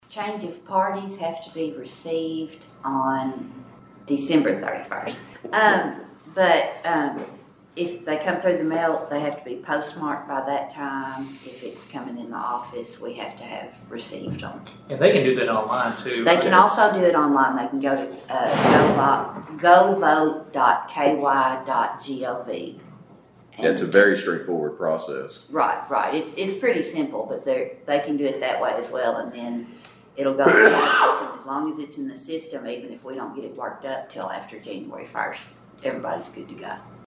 Caldwell County Fiscal Court heard updates on proposed and ongoing community projects during a brief meeting on Tuesday morning.
During department reports, County Clerk Jennifer Hale reminded voters that the deadline to change their party affiliation is at the end of this month.